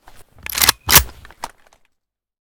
an94_unjam.ogg